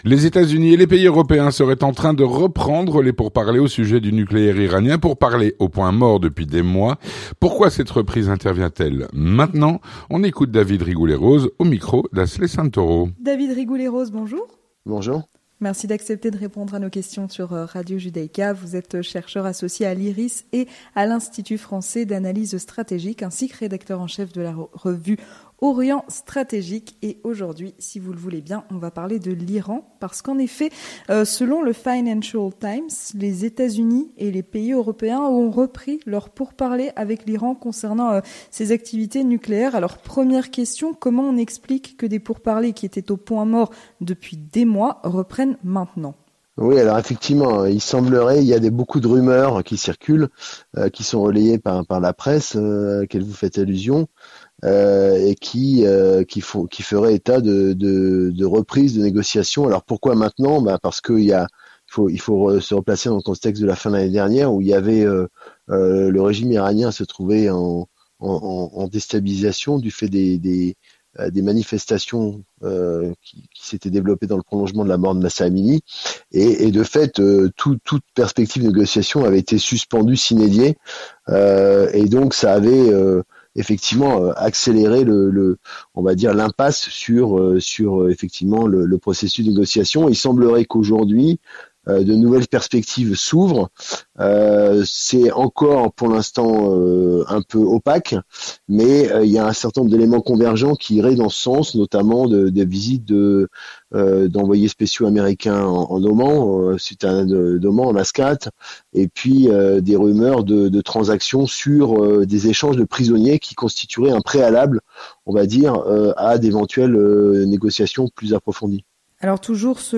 Entretien du 18h - Reprise des pourparlers sur le nucléaire iranien